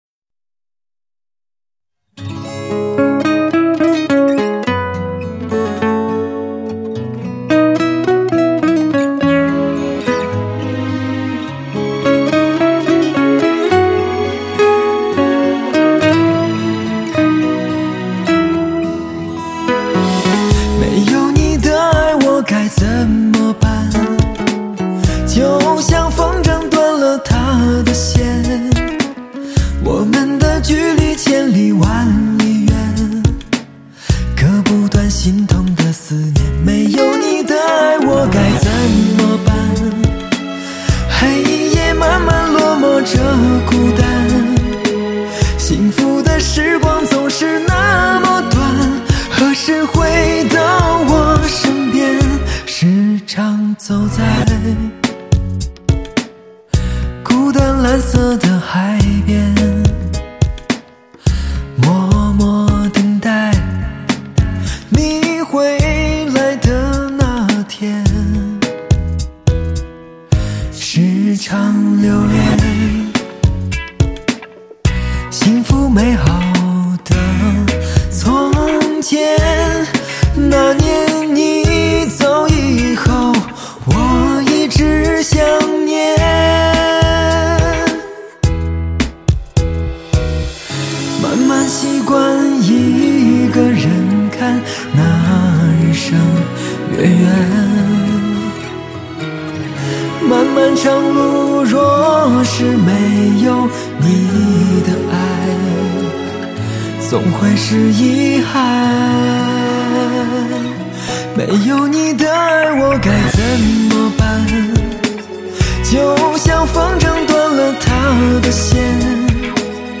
疗伤系超细腻巨蟹男声